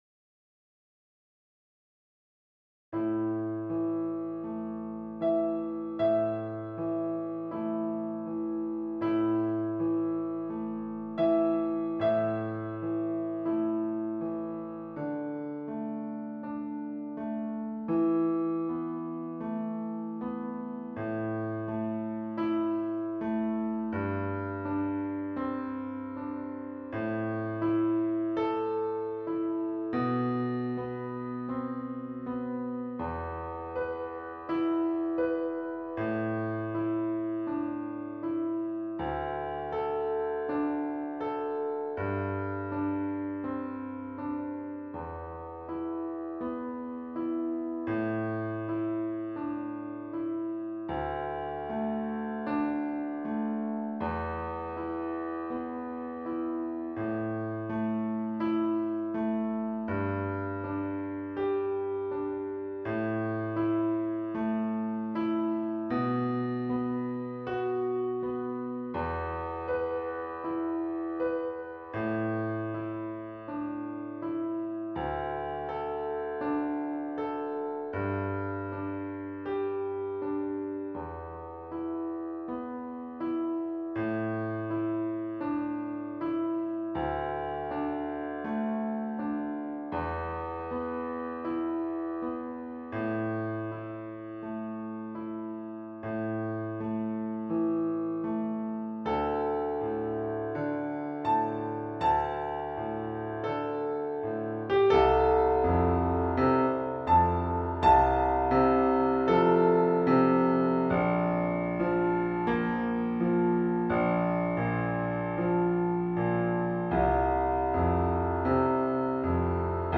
Melodiestimme in langsamem Übungs-Tempo